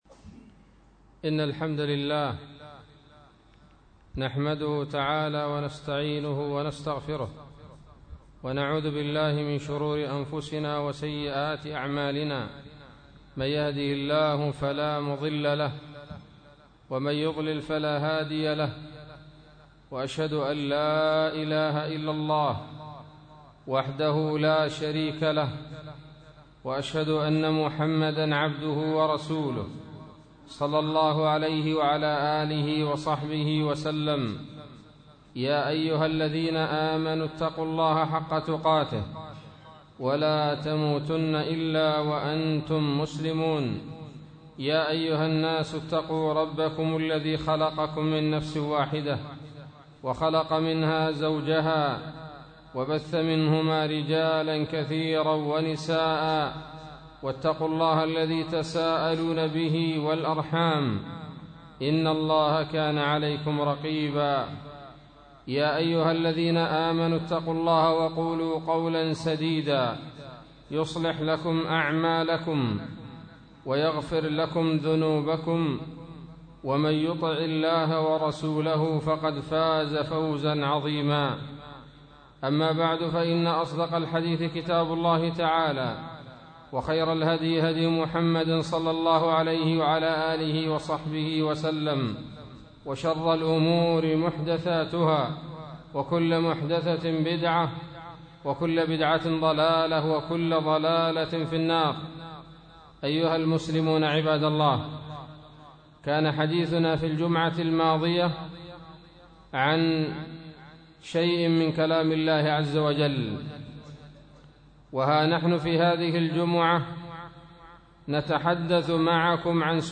خطبة بعنوان : ((تفسير سورة التكاثر)) 21 ربيع الأول 1437 هـ